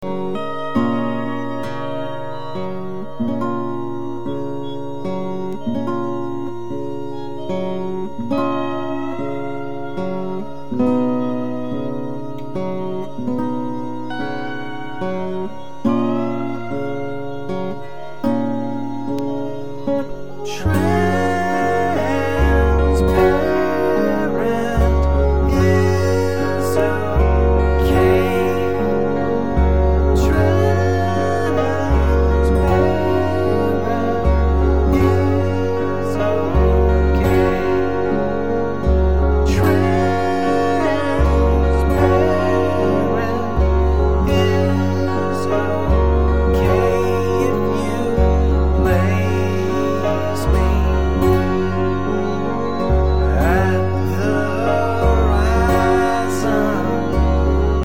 Catalogado quase sempre como pós-rock